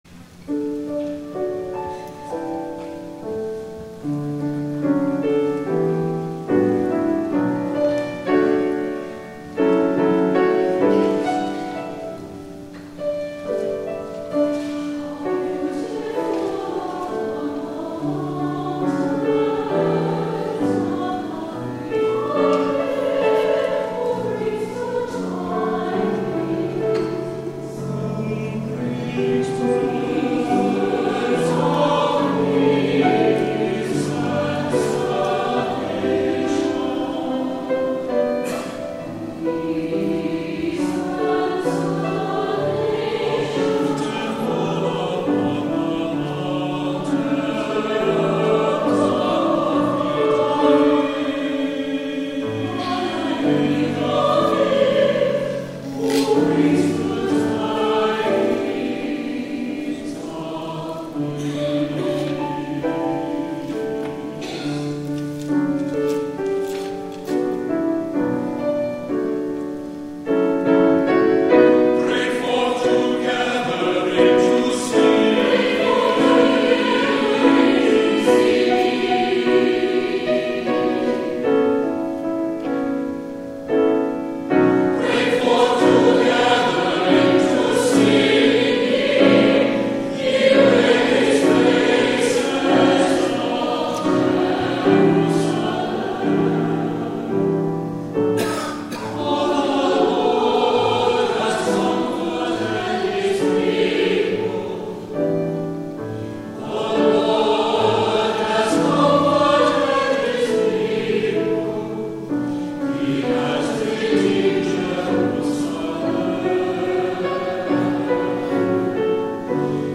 11 a.m. WORSHIP
THE ANTHEM